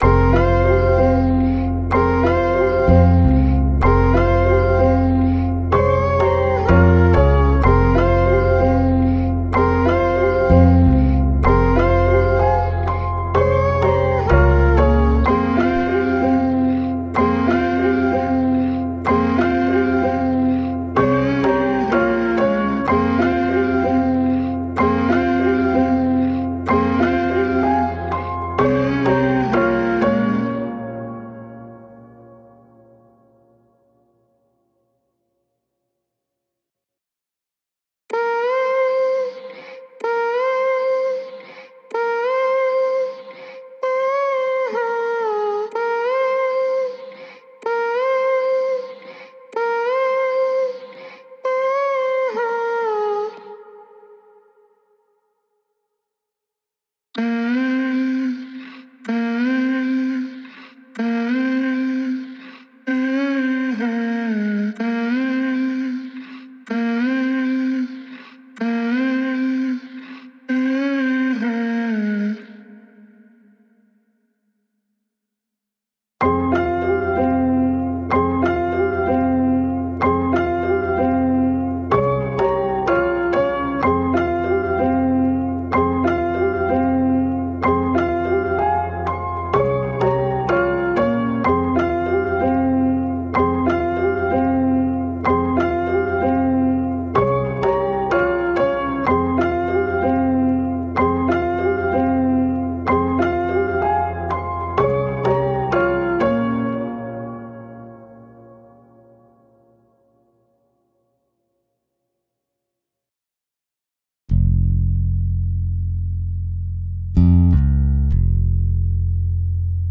پکیج سمپل برای سبک هیپ هاپ Drumkit Vol.2 | دانلود سمپل رایگان | لوپ و سمپل رایگان
6. Sample 126 bpm.mp3